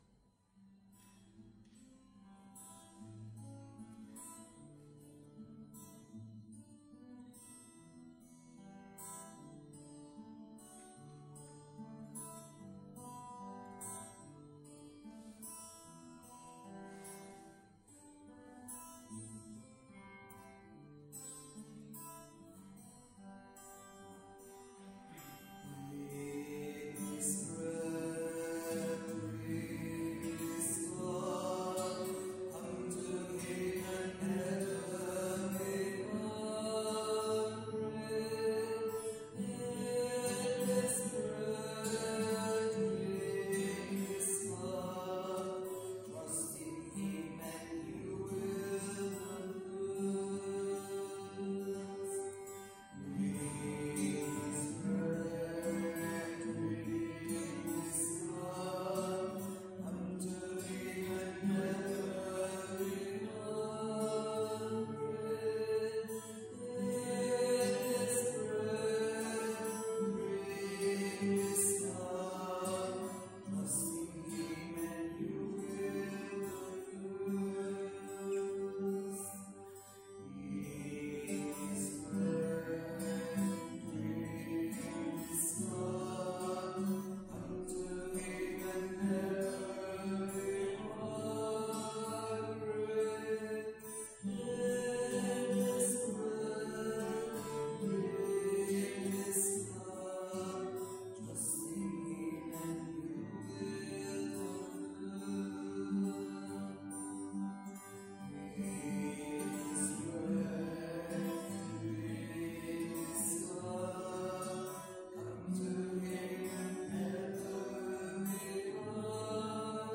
Pregària de Taizé a Mataró... des de febrer de 2001
Església de Santa Anna - Diumenge 23 d'octubre de 2022
Vàrem cantar...